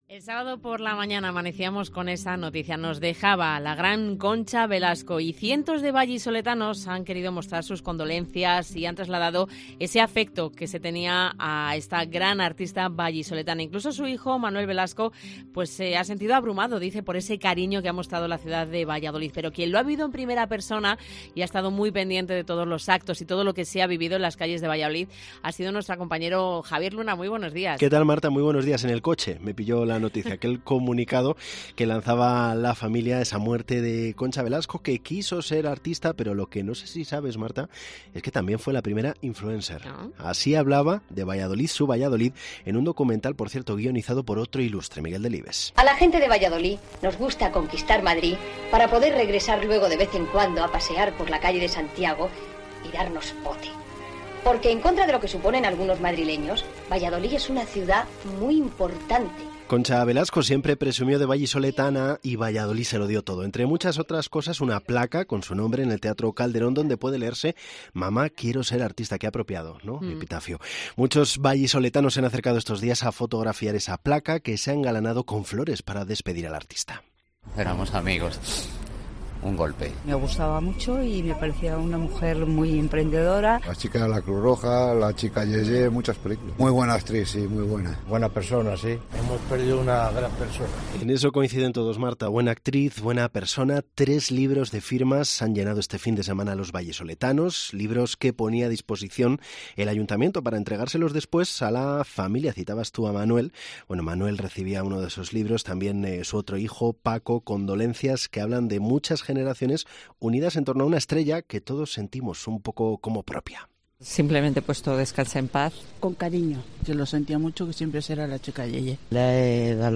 Los sonidos del multitudinario último adiós a Concha Velasco, la eterna 'Chica Ye Ye'
Pero miles de vallisoletanos la hicieron calurosa con sus vítores y aplausos al paso del cortejo fúnebre con los restos mortales de Concha Velasco, fallecida el sábado 2 de diciembre a los 84 años de edad.